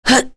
Tanya-Vox_Attack4.wav